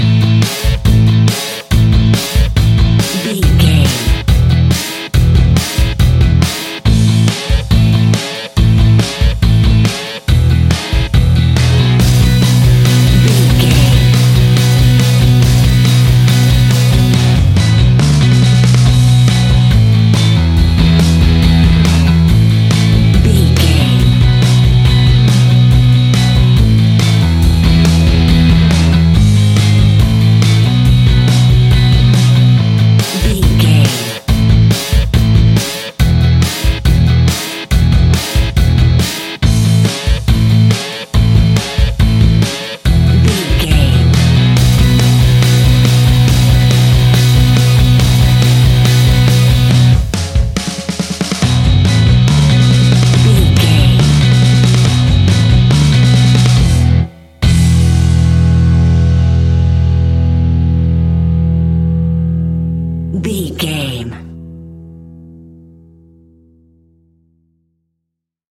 Ionian/Major
indie pop
fun
energetic
uplifting
upbeat
groovy
guitars
bass
drums
piano
organ